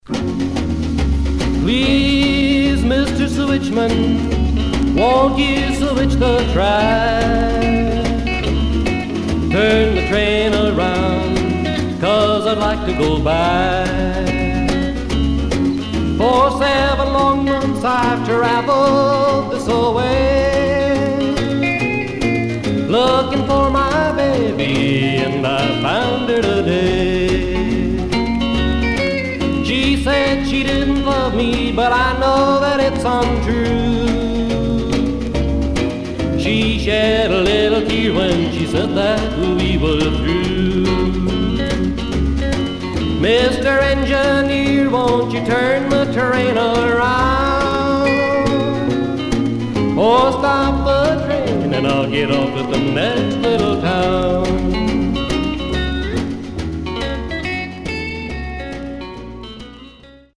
Mono
Country